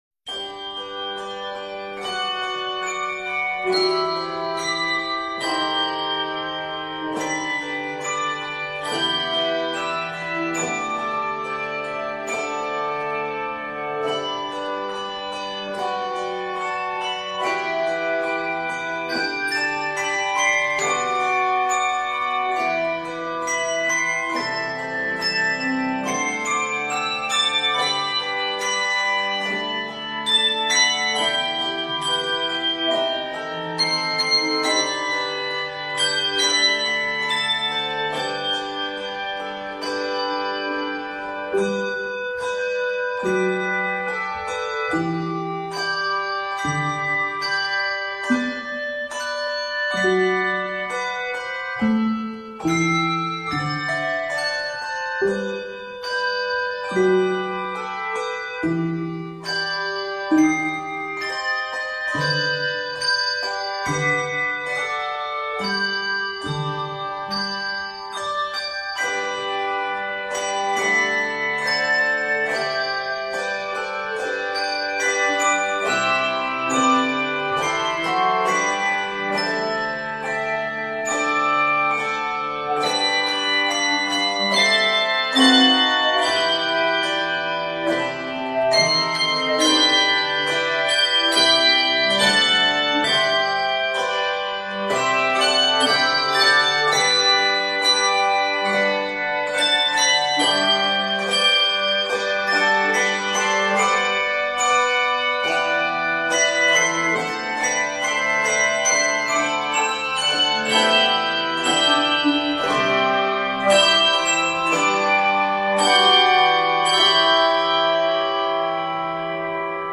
sensitive and expressive setting